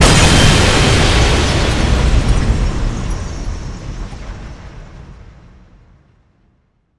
ОЧЕНЬ ГРОМКО ЗВУК ВЗРЫВА ДЕТОНАТОРА